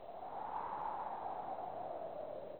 ambient_wind.wav